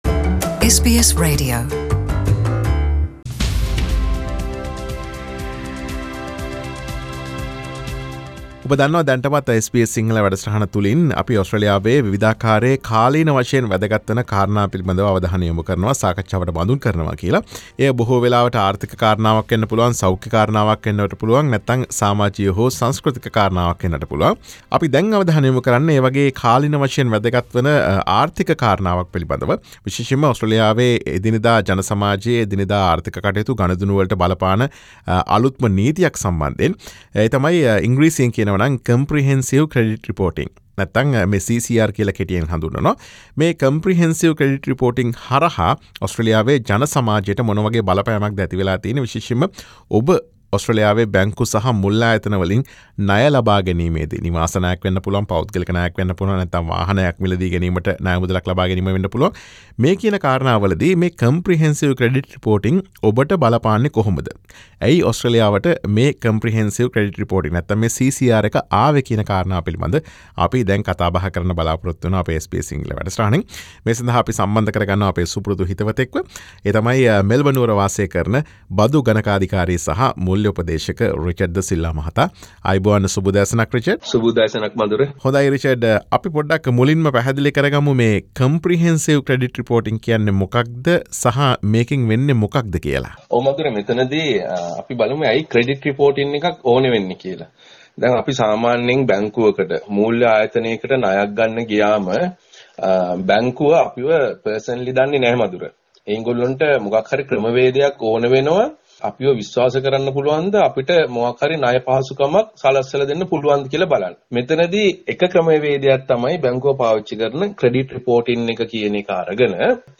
SBS සිංහල වැඩසටහන සිදු කළ සාකච්ඡාව.